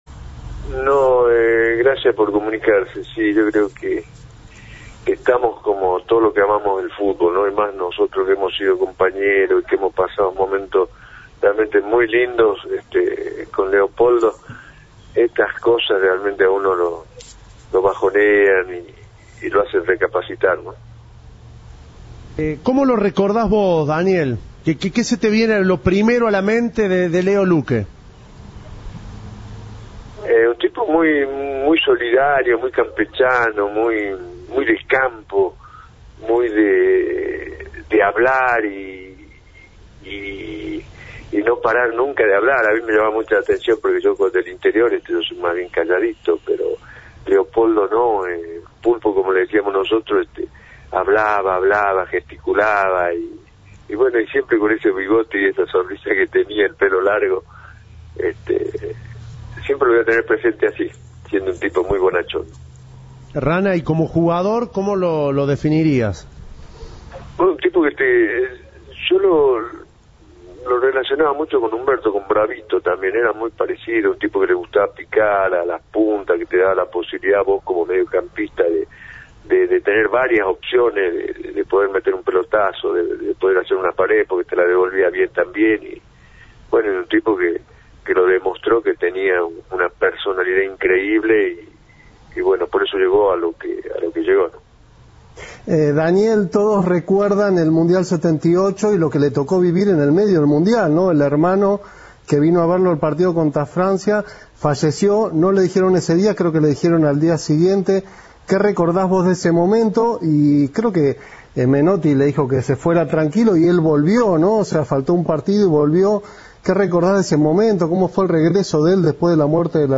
Entrevista de Tiempo de Juego.